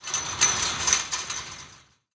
ambienturban_10.ogg